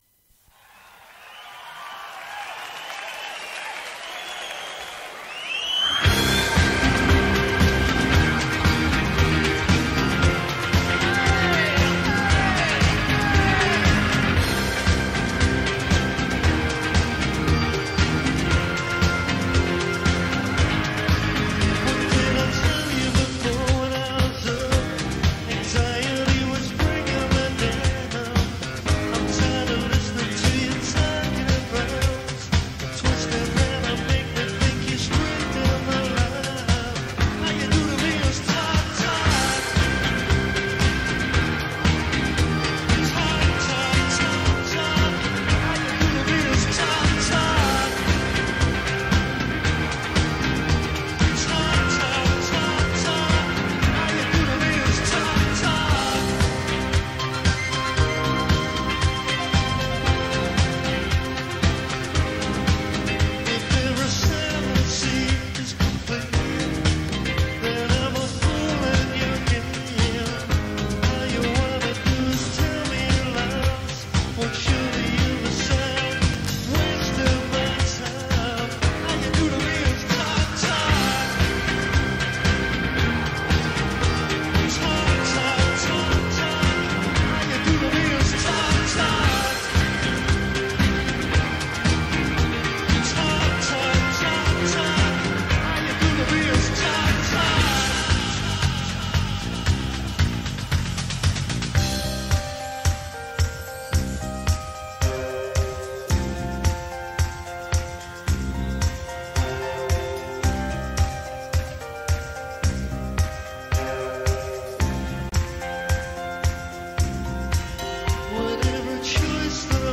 Pioneers of Post-Rock